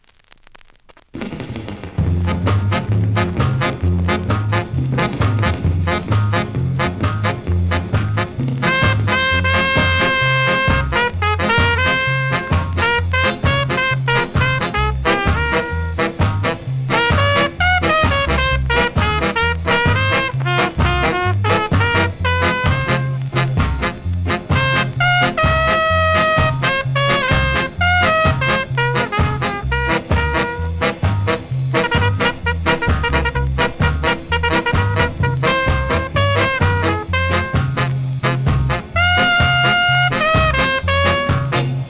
細かなキズ多めですが音は良好なので試聴で確認下さい。